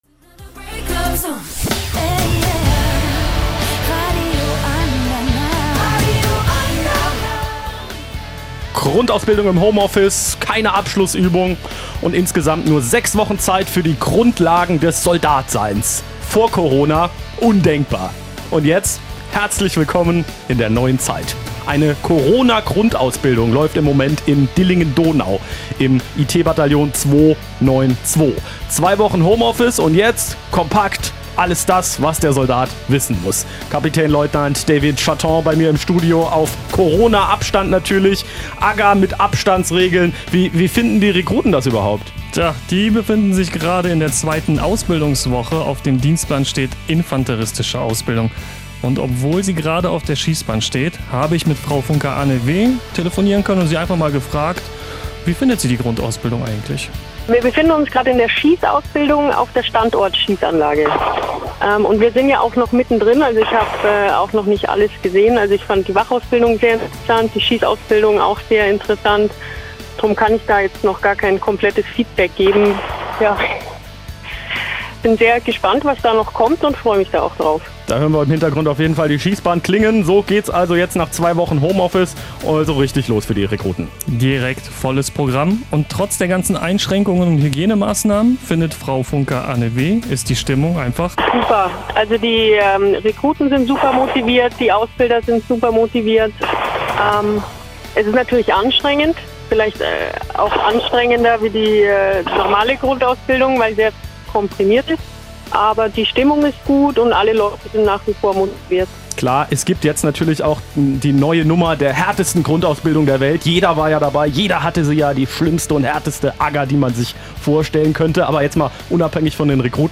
Im Kollegengespräch: Radio Andernach über die Grundausbildung in Dillingen Teil 2